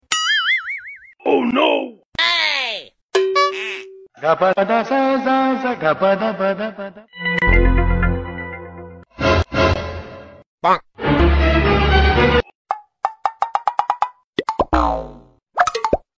Viral Memes Sounds Effects.